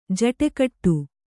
♪ jaṭe kaṭṭu